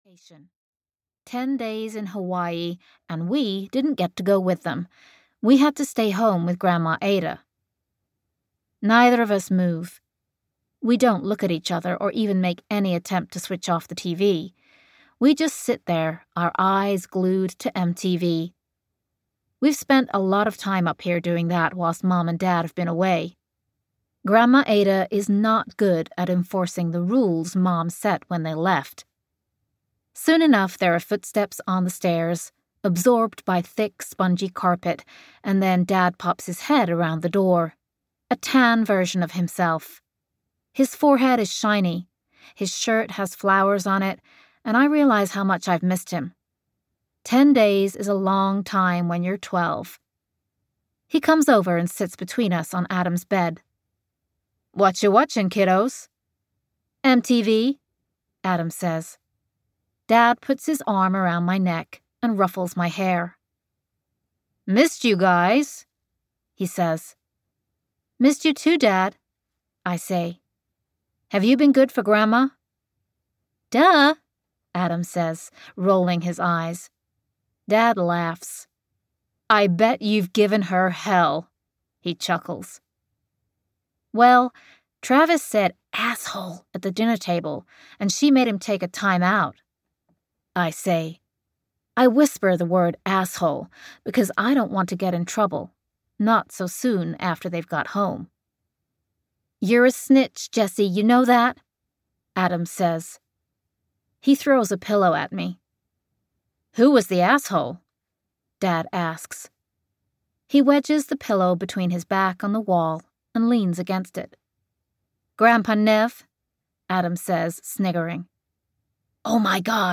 Call Me, Maybe (EN) audiokniha
Ukázka z knihy